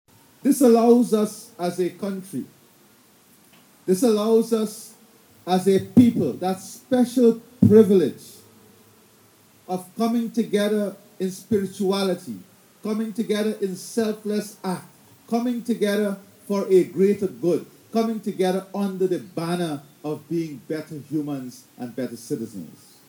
Speaking from the Cove and John Ashram, President Ali highlighted the universal relevance of the lessons gleaned from Maha Shivratri, a sacred Hindu festival venerating Lord Shiva.